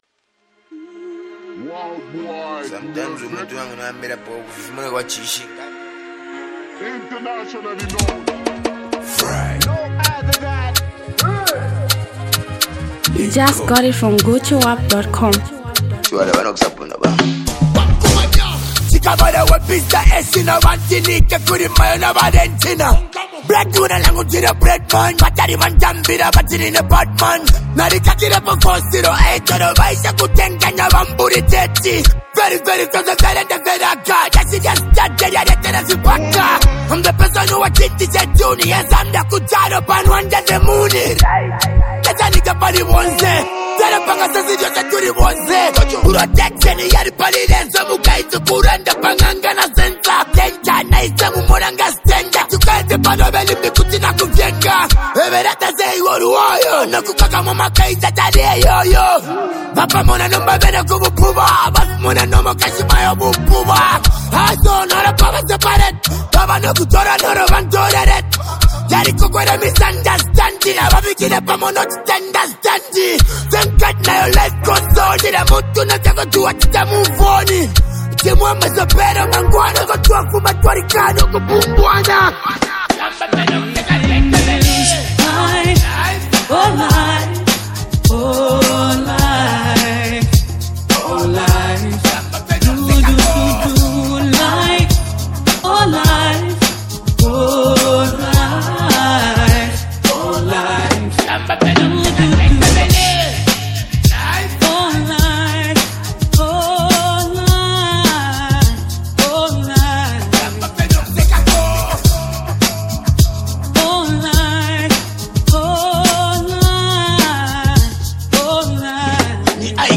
Zambian Mp3 Music
street anthem